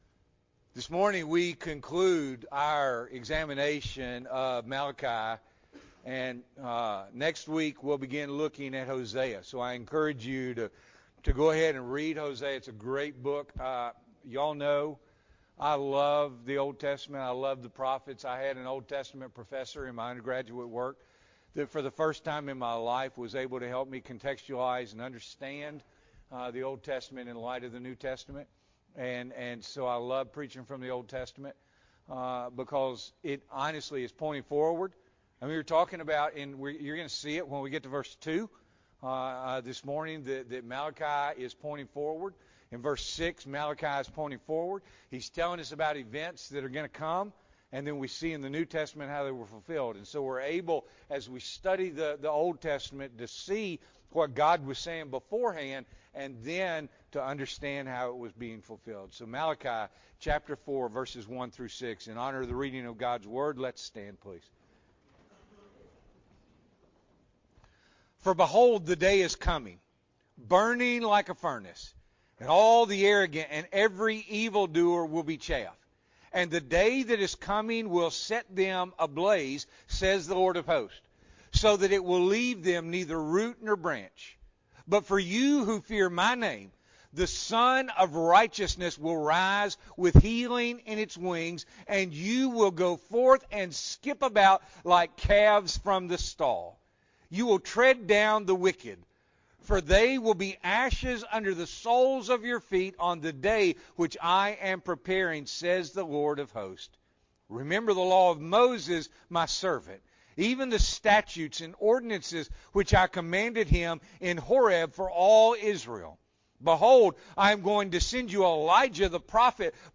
June 26, 2022 – Morning Worship